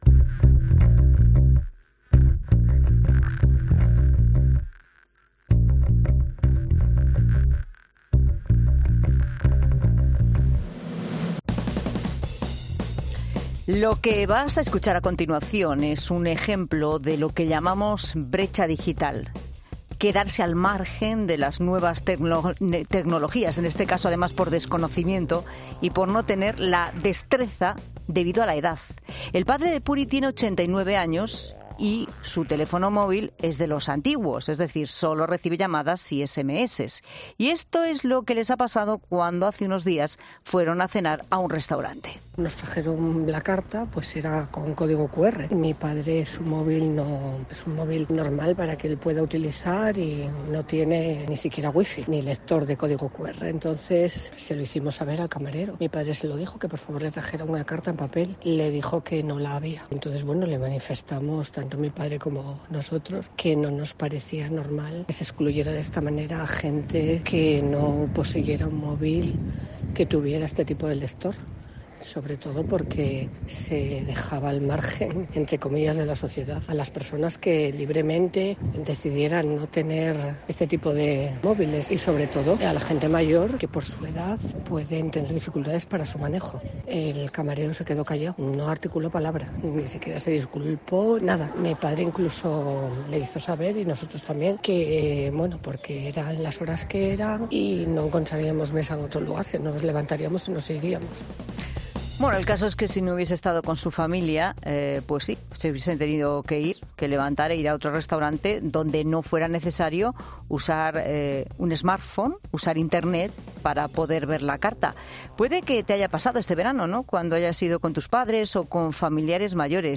En 'La Tarde' recibimos distintos testimonios de cómo es la situación de acceso al mundo digital